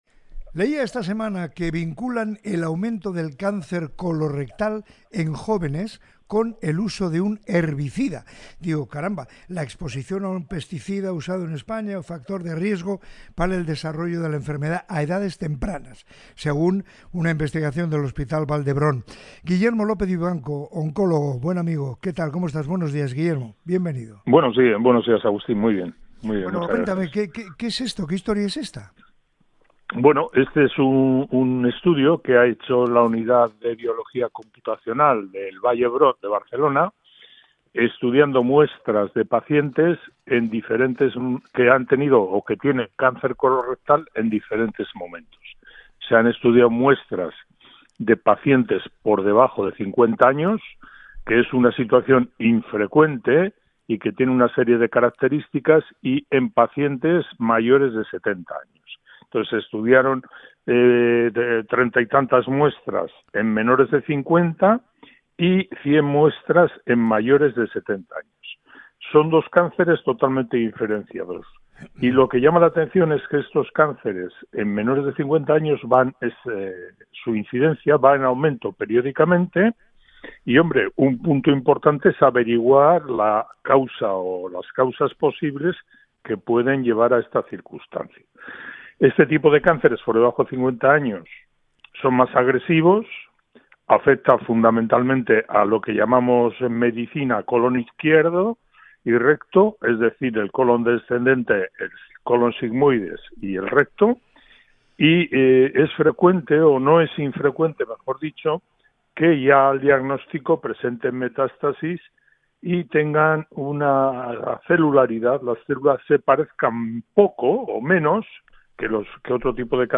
Entrevista sobre cáncer y juventud